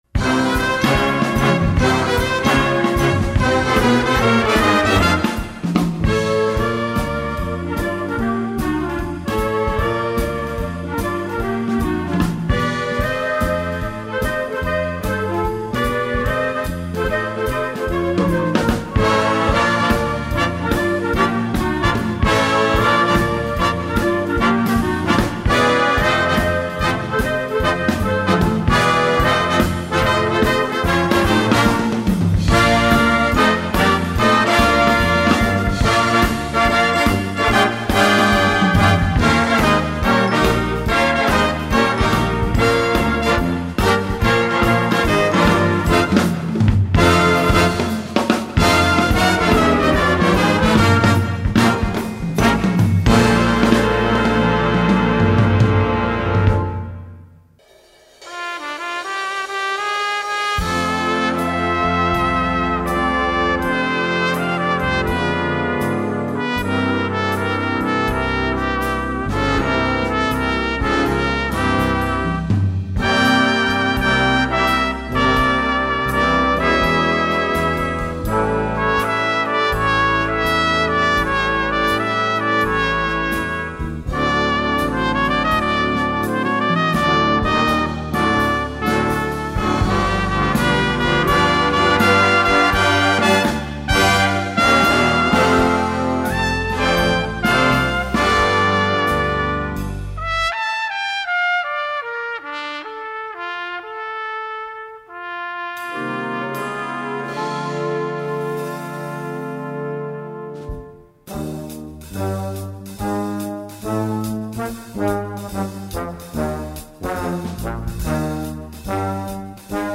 B-C Besetzung: Blasorchester Zu hören auf
A rousing salute to the music of the “Big Band Era”